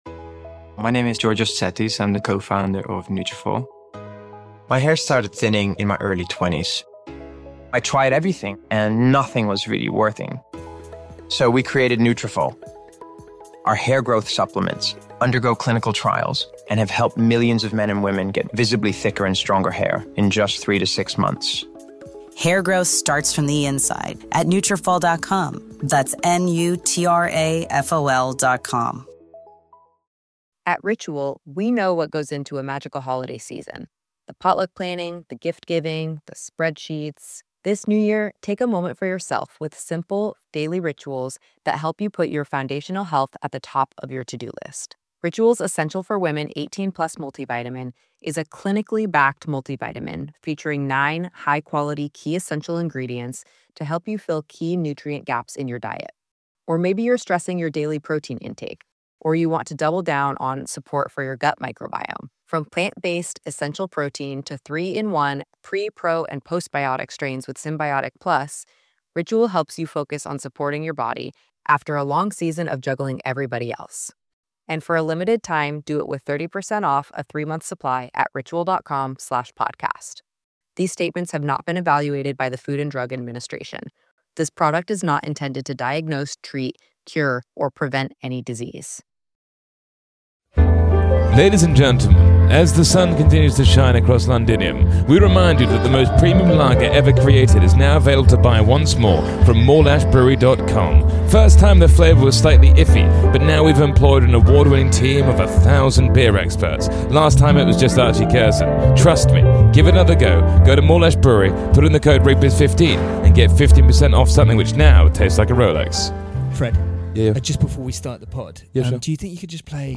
In his first long format interview since he retired, Hoggy sits down with us and shares countless incredibly hilarious ...